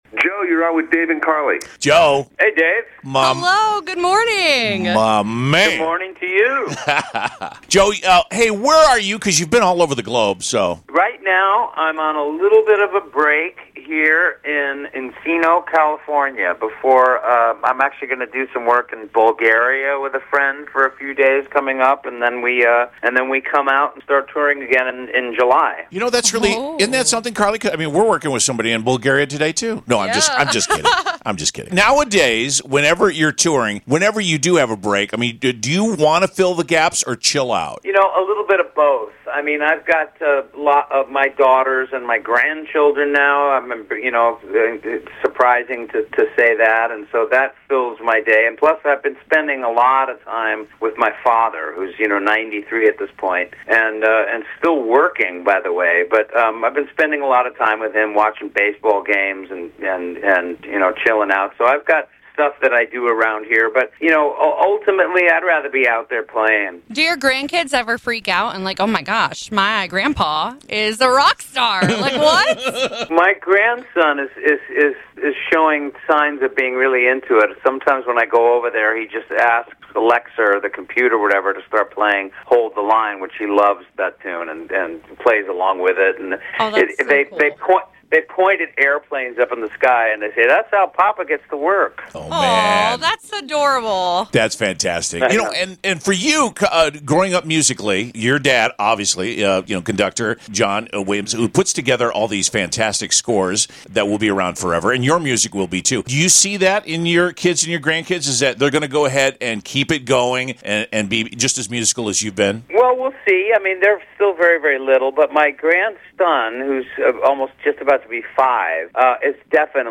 Toto has 1 Billion streams on one of their songs and 2 billion on another..hear the story from Toto lead singer, Joe Williams!
Hear the amazing stats on “Hold The Line” and “Africa”…from lead singer Joe Williams in our interview here…..